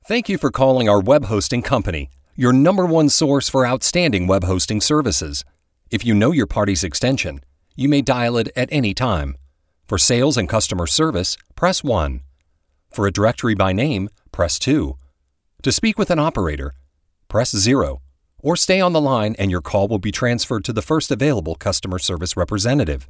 Male
American English (Native)
Authoritative, Confident, Corporate, Deep, Engaging, Friendly, Natural, Reassuring, Versatile, Assured, Conversational, Cool, Gravitas, Smooth, Upbeat, Warm, Young
Microphone: Stellar X2
Audio equipment: Sound proof home studio